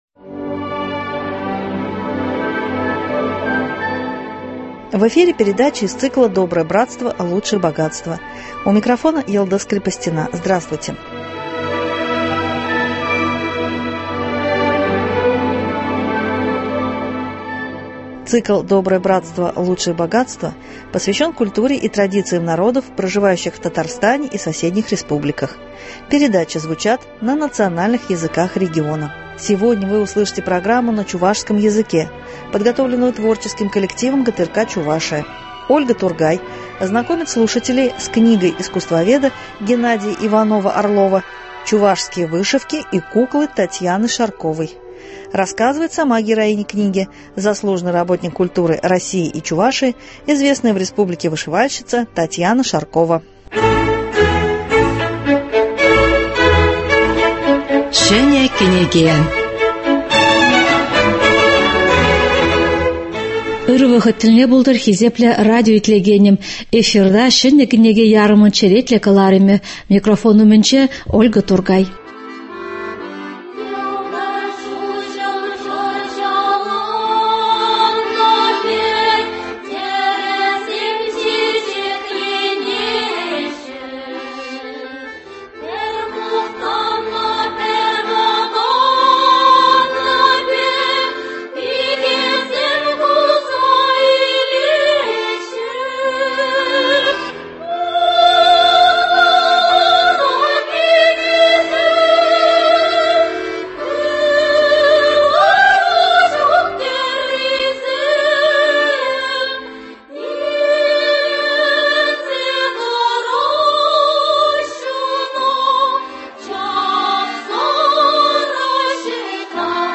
Сегодня вы услышите передачу на чувашском языке, подготовленную творческим коллективом ГТРК Чувашия.